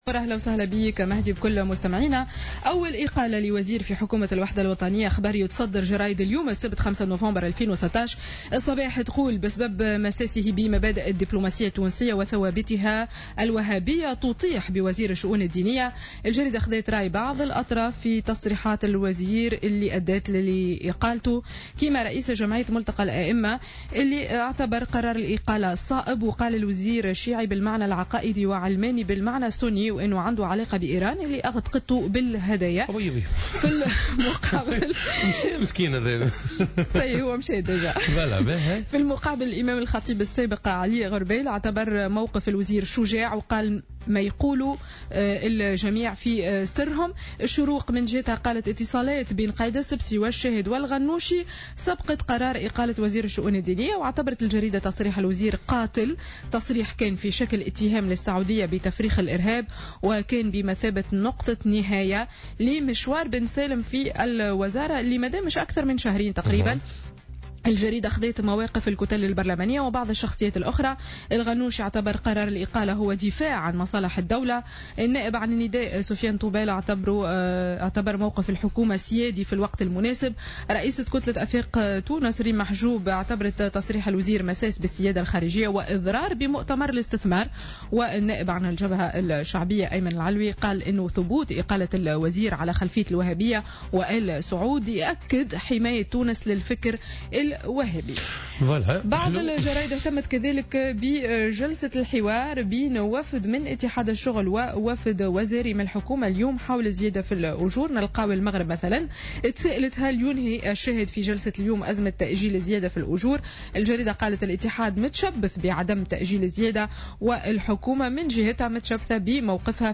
Revue de presse du Samedi 5 Novembre 2016